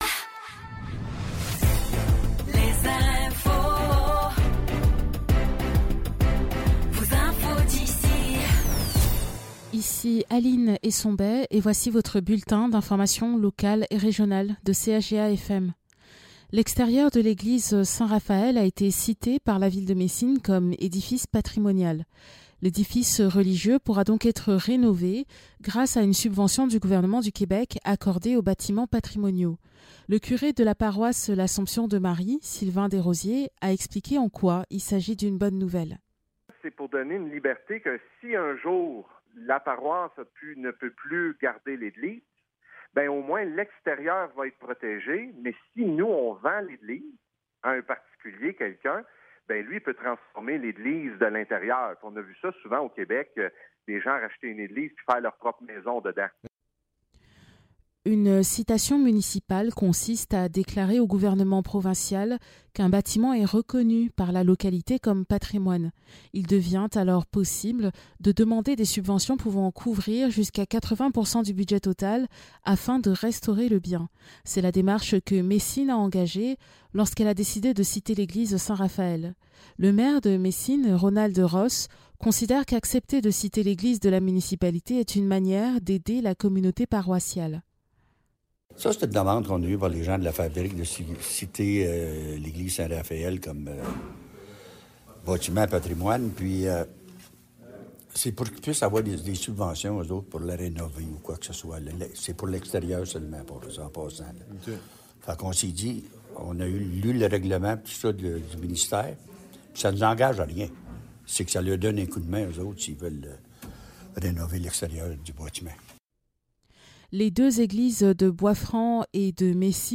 Nouvelles locales - 12 mars 2024 - 12 h